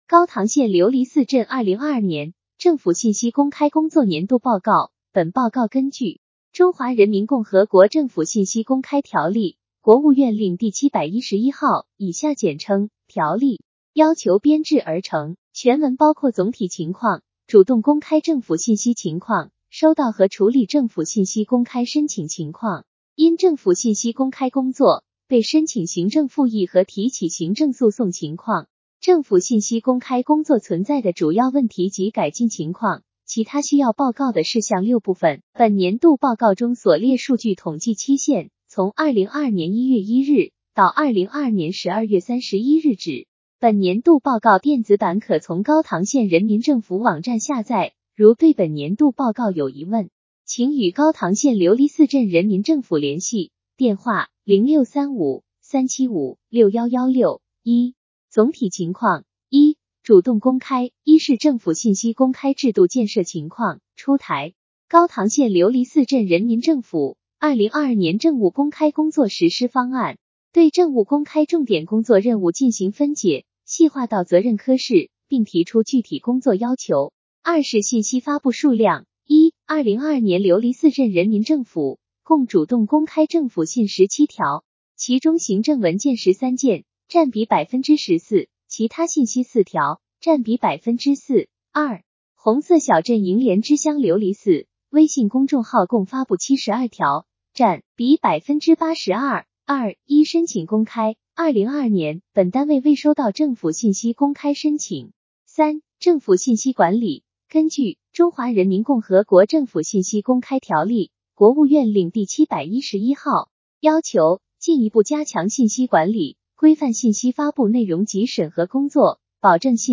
高唐县琉璃寺镇2022年政府信息公开工作年度报告语音朗读